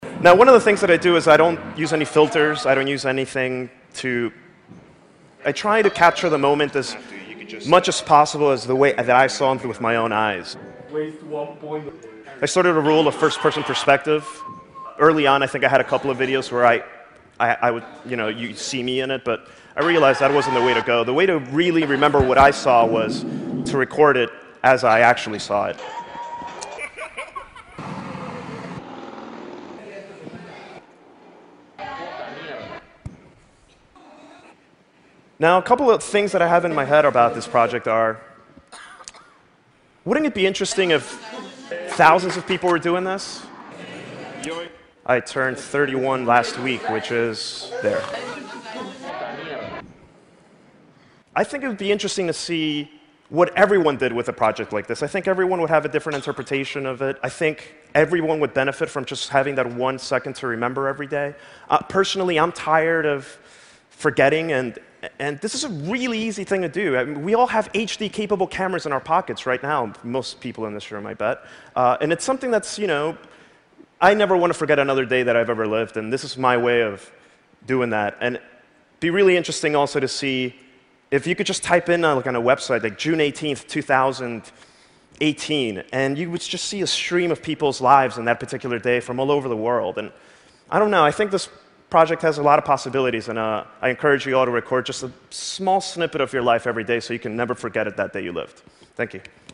TED演讲：每天一秒钟(5) 听力文件下载—在线英语听力室